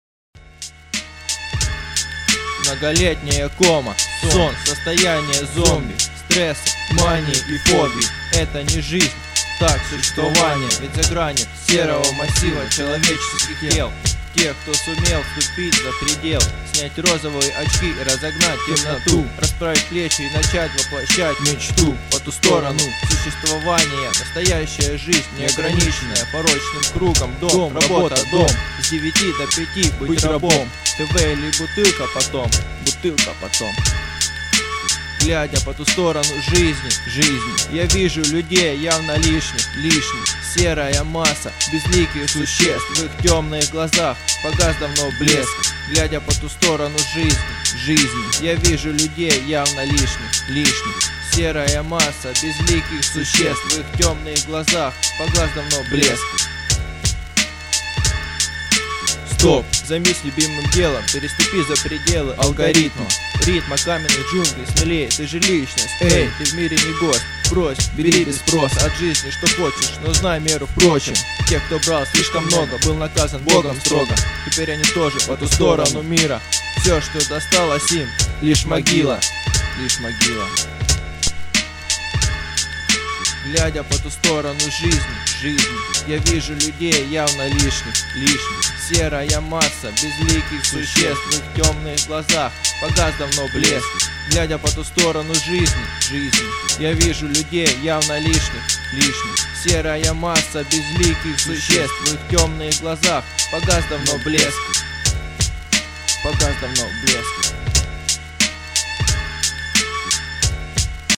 • Жанр: Рэп
1 раунд 1 MP3-баттла на форуме BadB.